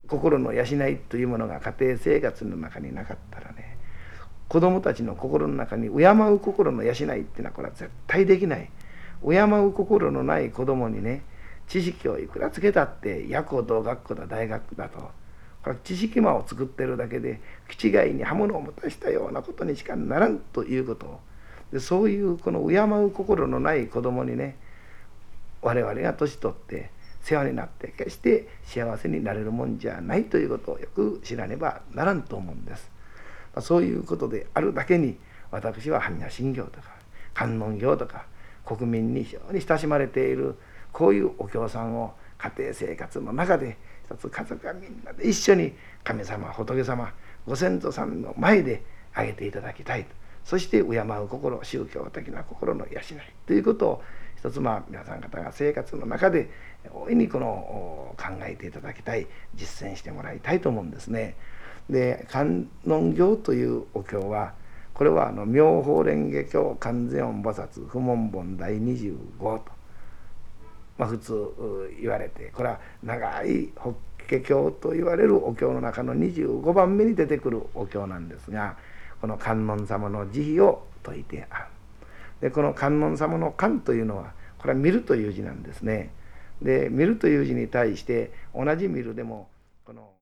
講話
20th century   contemporary   field recording   non music   orchestra   post modern   spoken word